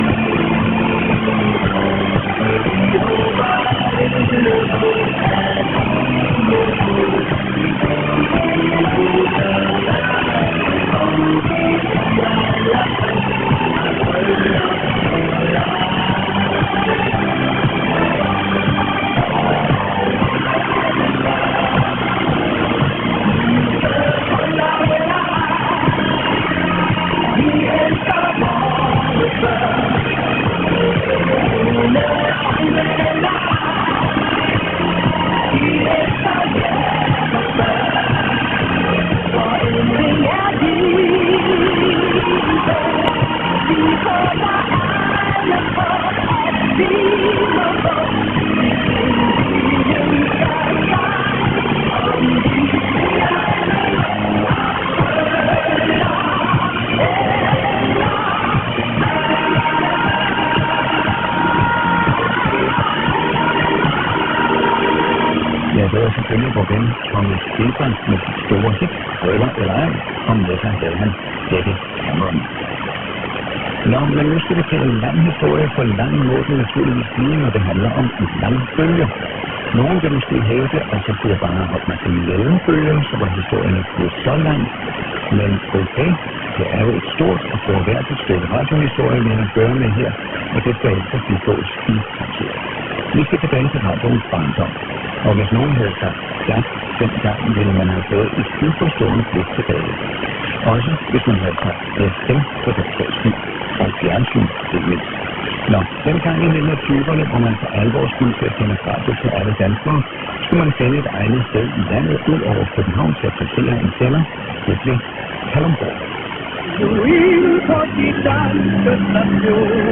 DR Kalundborg 243 kHz last transmission 31/12/2023